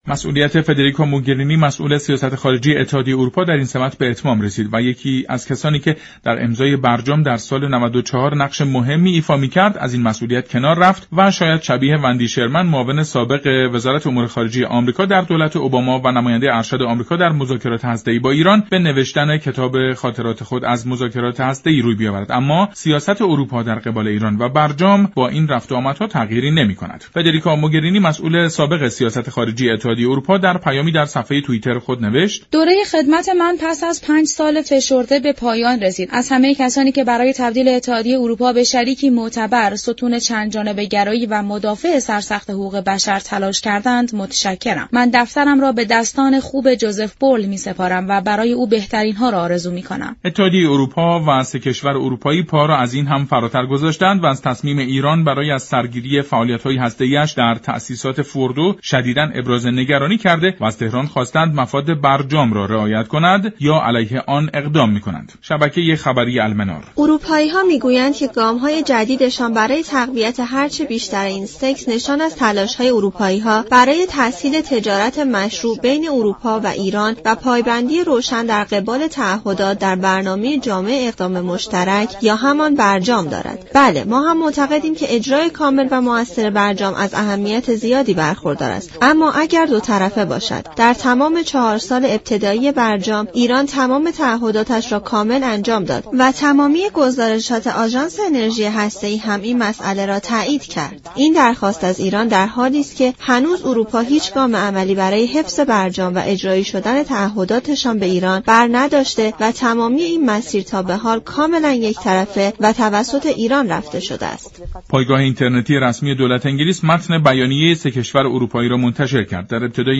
كارشناس مسائل سیاسی در گفت و گو با برنامه «جهان سیاست»